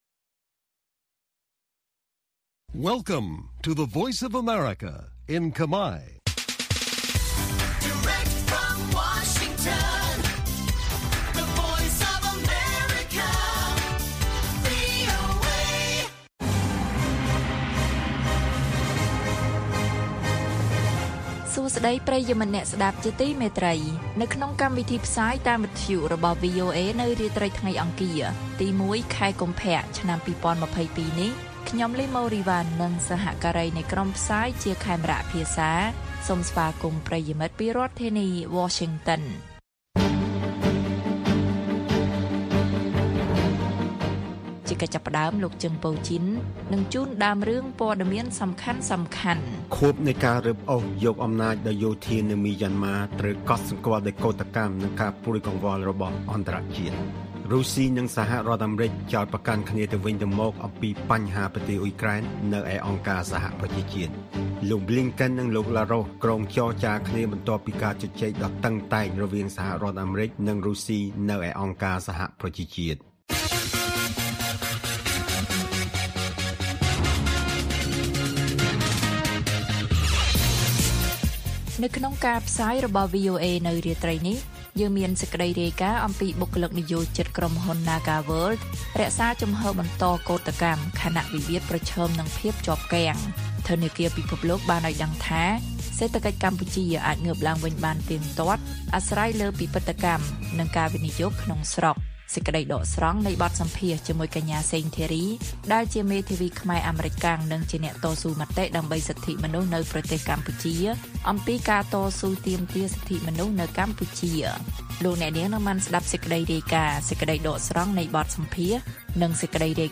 ព័ត៌មានពេលរាត្រី៖ ១ កុម្ភៈ ២០២២